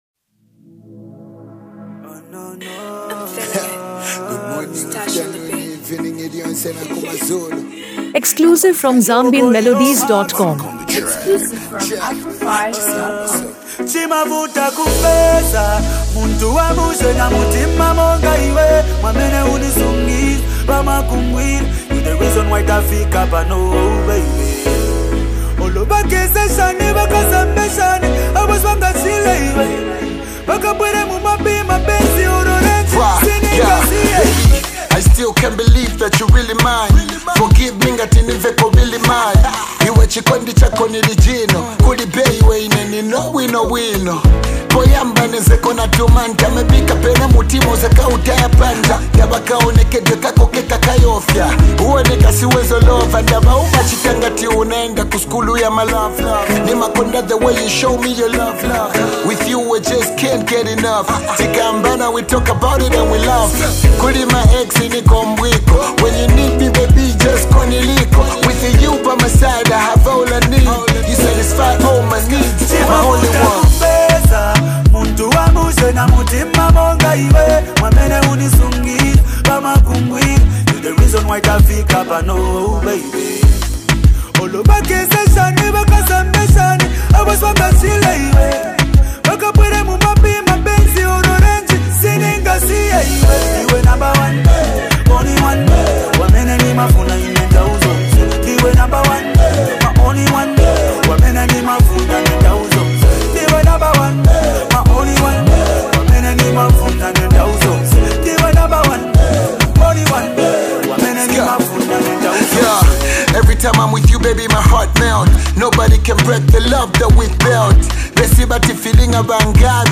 Zambian Music
The song falls within the Afro-pop and hip-hop fusion genre
With its catchy rhythm and polished production